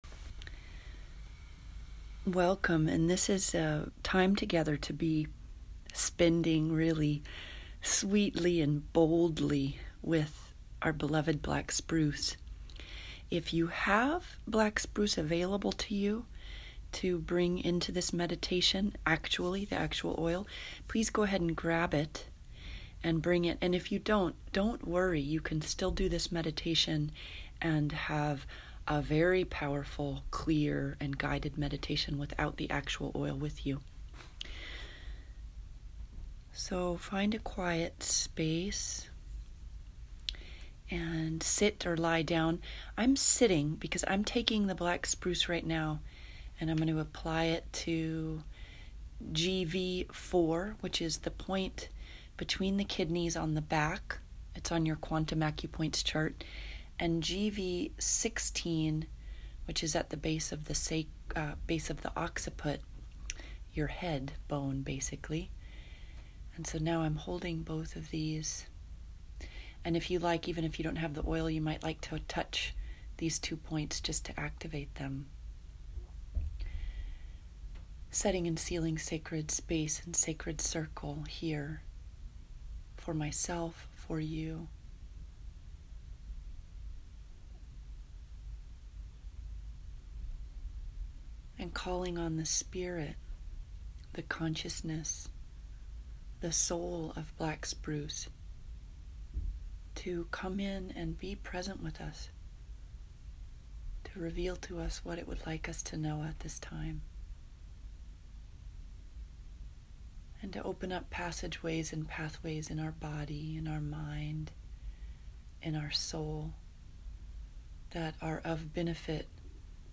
Black Spruce Guided Meditation